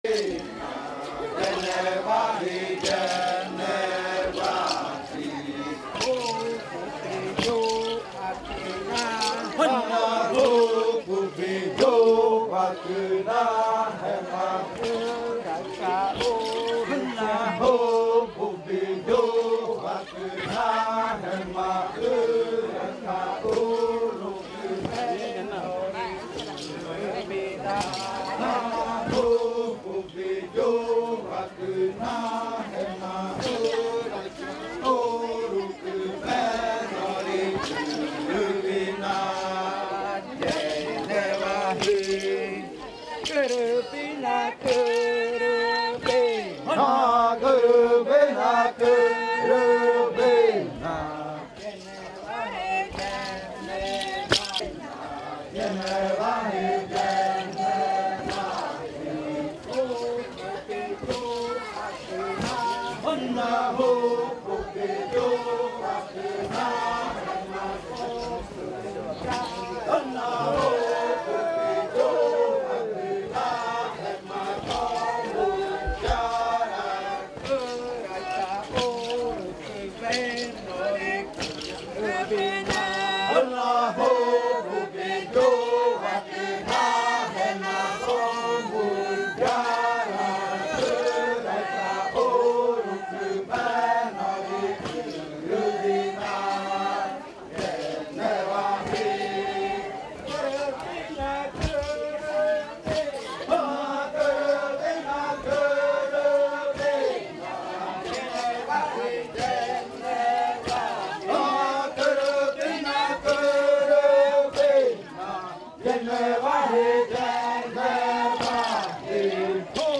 Canto de la variante muinakɨ
Leticia, Amazonas
con el grupo de cantores bailando en la Casa Hija Eetane. Este canto se interpretó en el baile de clausura de la Cátedra de lenguas "La lengua es espíritu" de la UNAL Sede Amazonia.